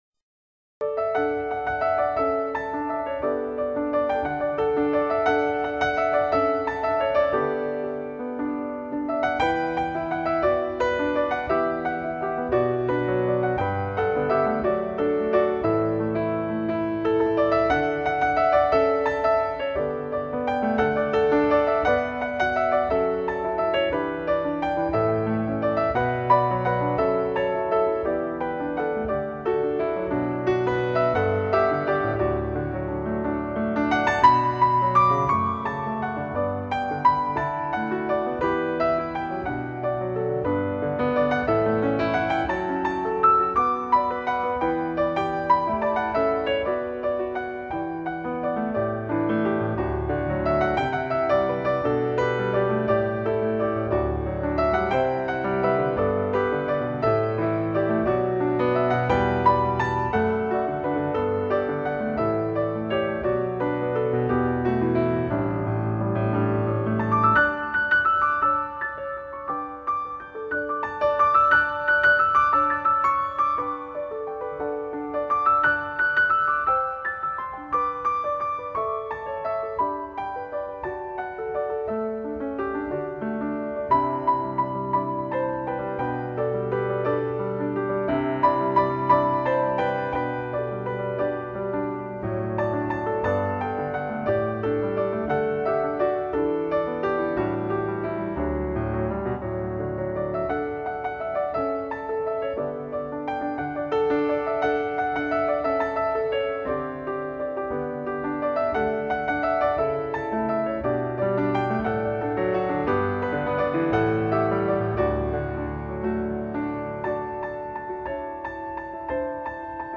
类　别： NewAge
清澈而没有过多的粘稠，温柔却又溪水长流。
更加入柔情的小提琴和单簧管伴奏，听起来非常温暖和舒适。
在清幽的音乐氛围里，清澈的钢琴音色，恰如其分地妆点出绮丽的光影，
融合大提琴与小提琴婉约动人的低诉，形成比例完美的弦乐合奏，